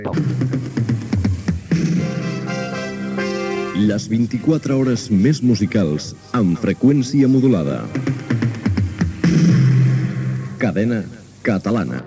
Indicatiu de la cadena.